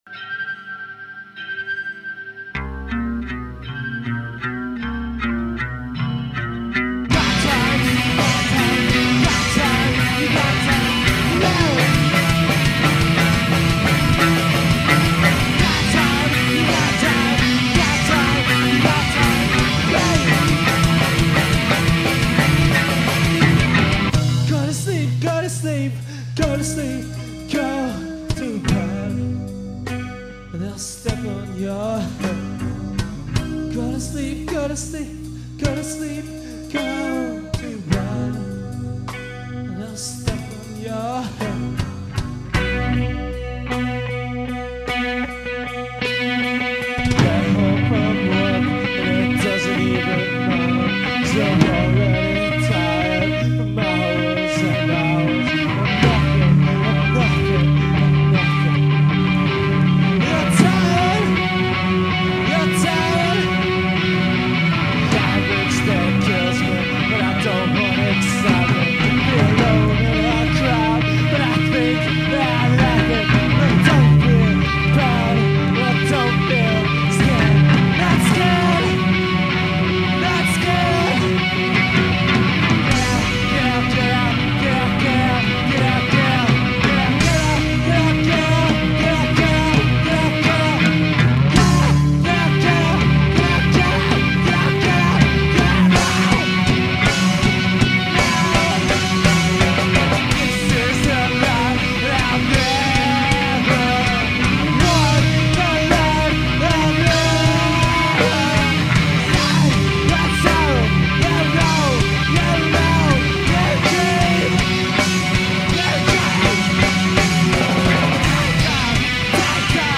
Vocals & Guitar
Drums
Bass & Vocals